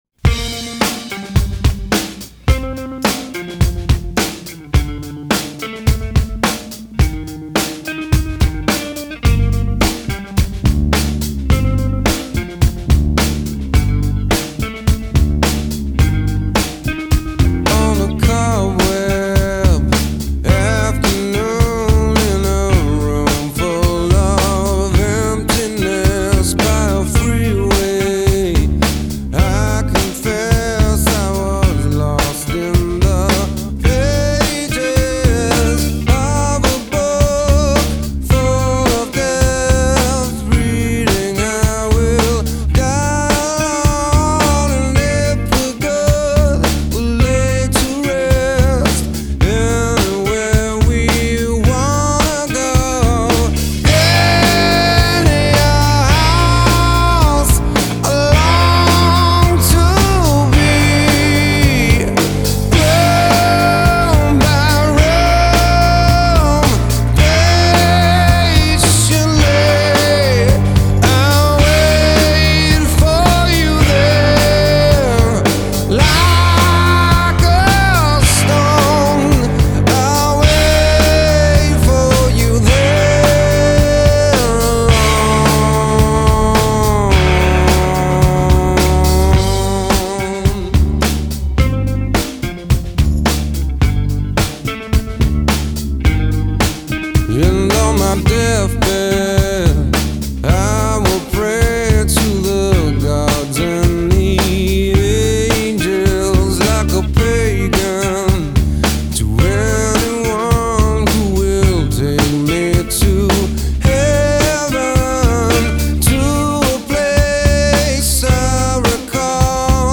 Трек размещён в разделе Зарубежная музыка / Рок.